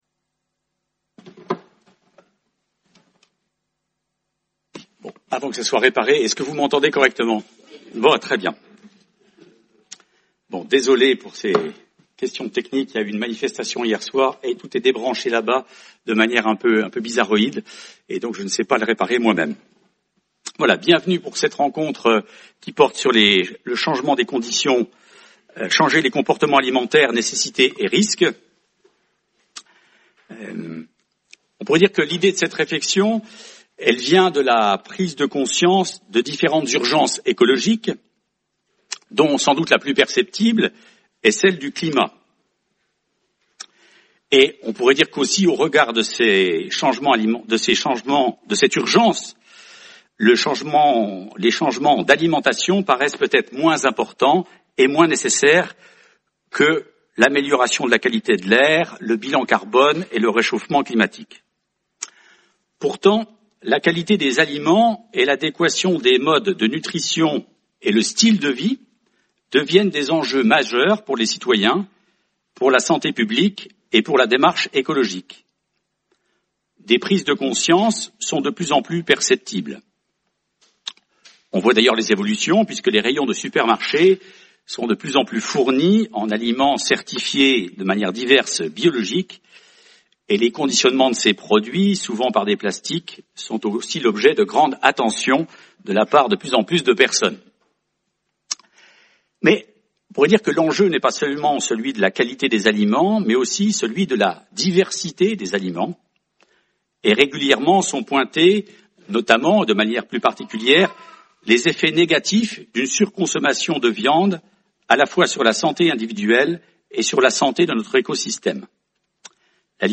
Colloque du 25 janvier 2020 du département d’éthique biomédicale.